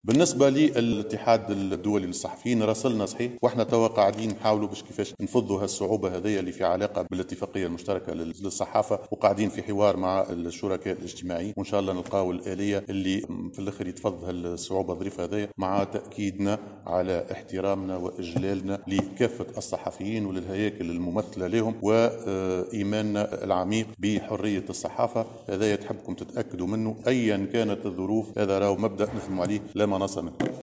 وشدّد المشيشي، على هامش إفتتاحه للندوة الثالثة للولاة بالعوينة، أنّ حرية الصحافة مبدأ لا مناص منه وفق قوله.